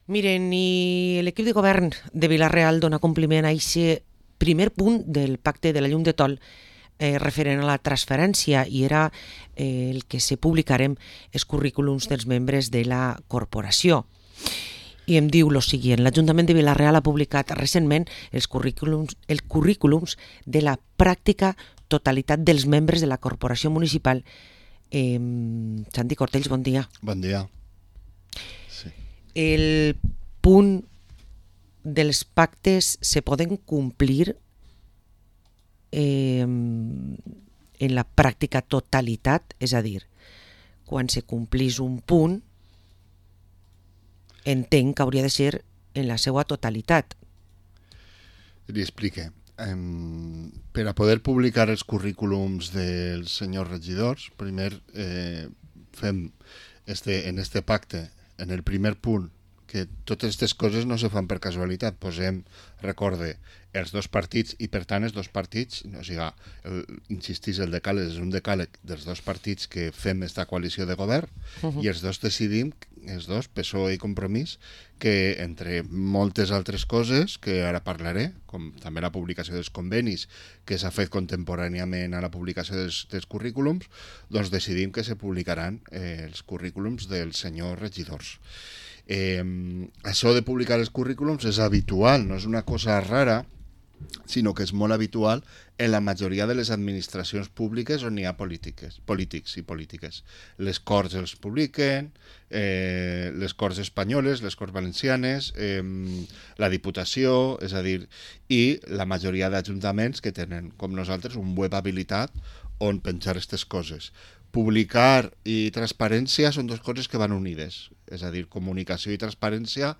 Parlem amb Santi Cortells, regidor de Transparència a l´Ajuntament de Vila-real